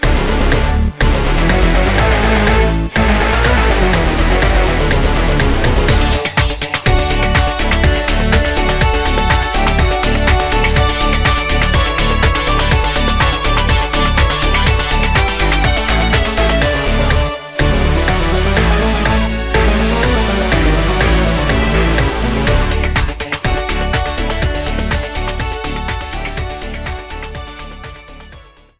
Classical music with a modern edge